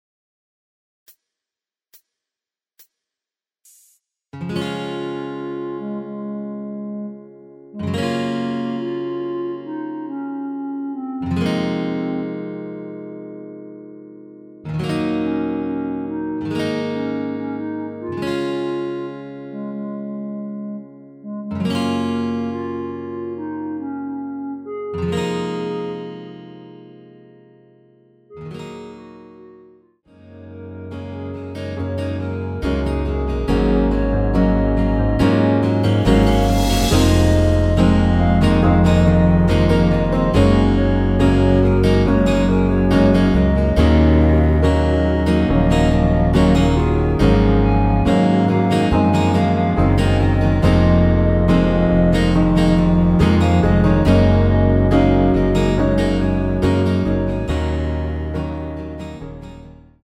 전주 없이 시작하는곡이라 인트로 카운트 넣어 놓았습니다.(미리듣기 참조)
원키에서(-1)내린 멜로디 포함된 MR입니다.
Db
앞부분30초, 뒷부분30초씩 편집해서 올려 드리고 있습니다.
중간에 음이 끈어지고 다시 나오는 이유는